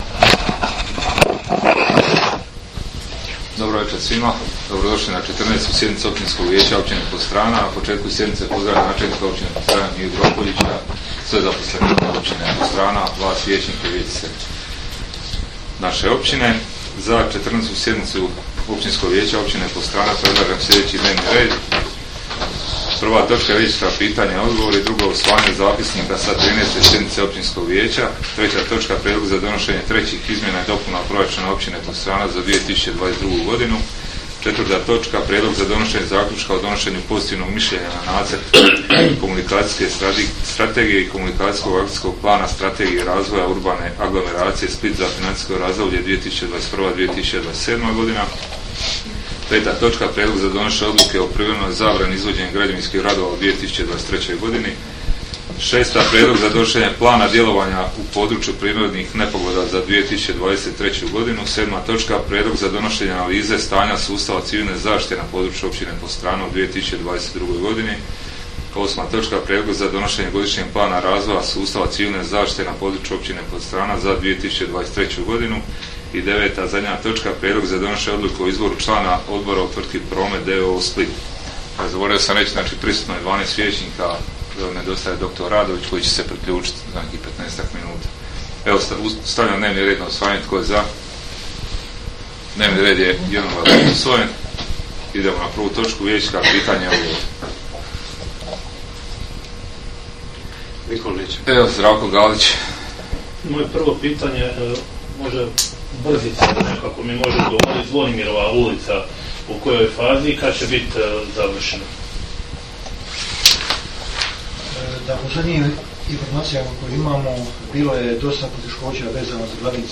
Sjednica će se održati dana 21. prosinca (srijeda) 2022. godine u 19,00 sati u vijećnici Općine Podstrana.